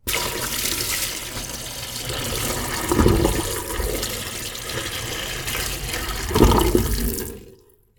台所
流しに水捨てる２
water_into_sink2.mp3